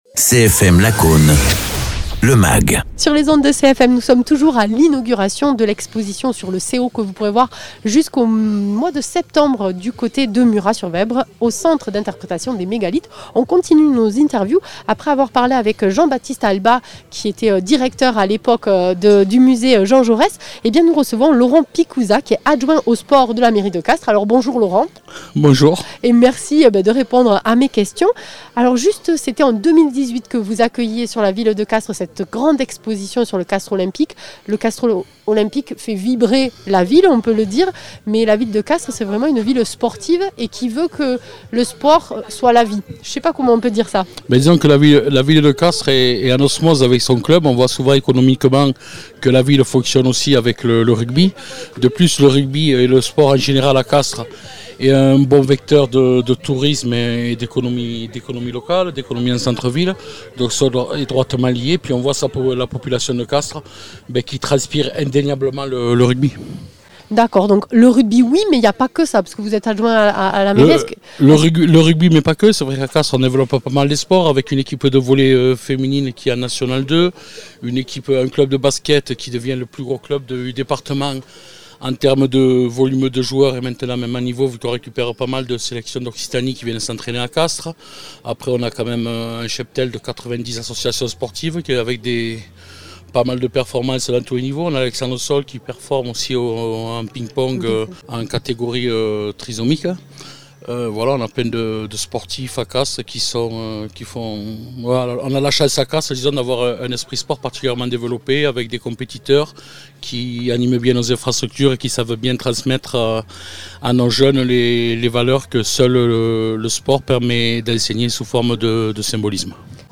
Interviews
Invité(s) : Laurent Picouza, adjoint au sport de la mairie de Castres (Tarn)